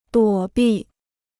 躲避 (duǒ bì) Free Chinese Dictionary